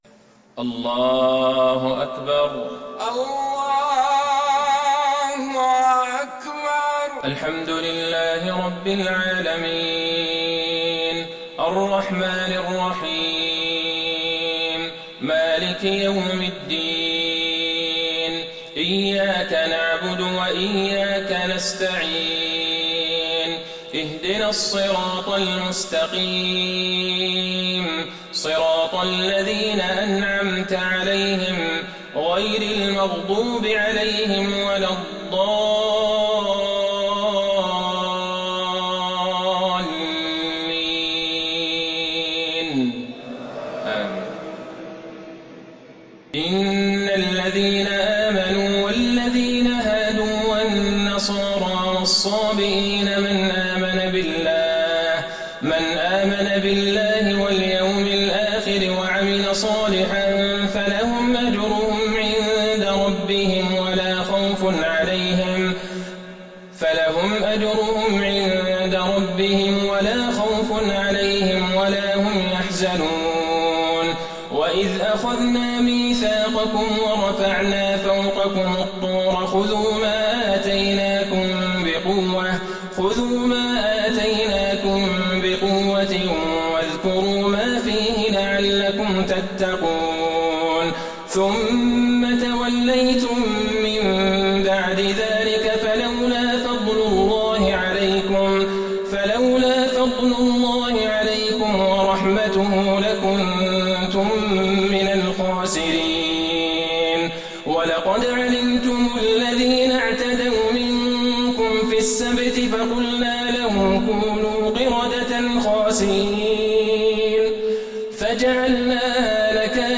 صلاة العشاء للقارئ عبدالله البعيجان 18 رجب 1441 هـ
تِلَاوَات الْحَرَمَيْن .